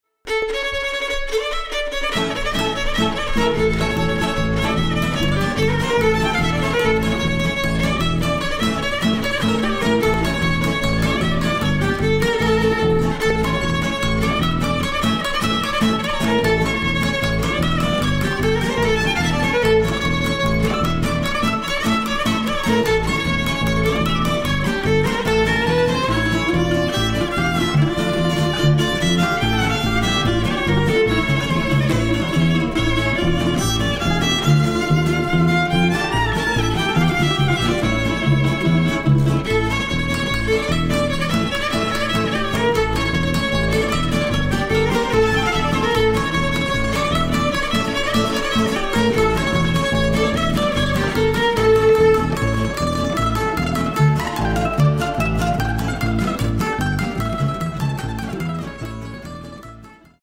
Klezmer und jiddische Lieder